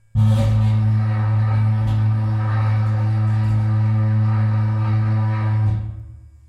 剃刀，打击乐器组" mid1 mid1
描述：电动剃须刀，金属棒，低音弦和金属罐。
Tag: 剃须刀 重复 电机 金属 金属加工 发动机